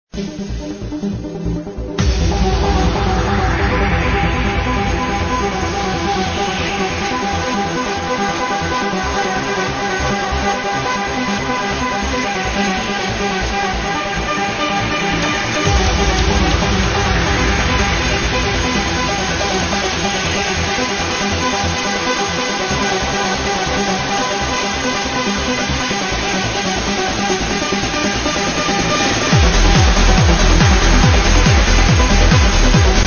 [Trance] Unknown melodic song
Very nice uplifting and melodic song.